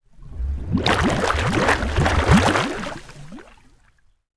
Water_29.wav